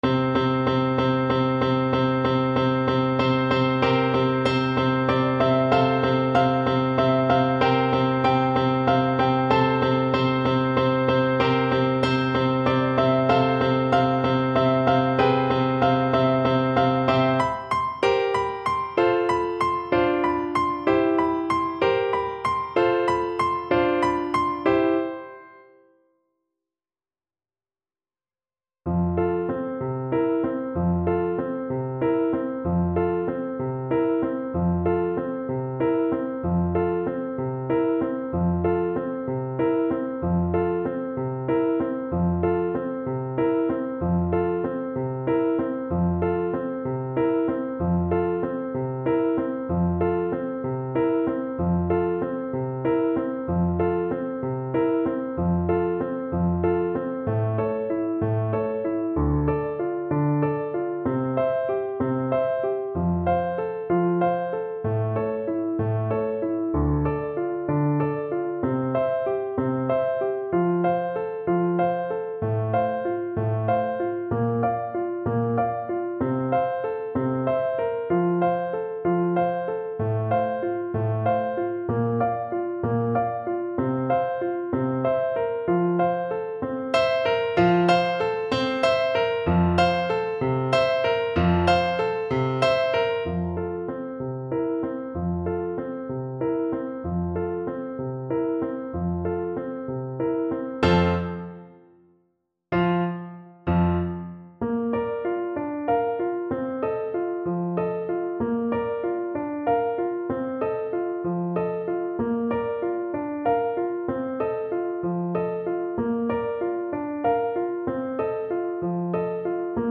6/8 (View more 6/8 Music)
.=96 Allegro (View more music marked Allegro)
Classical (View more Classical Flute Music)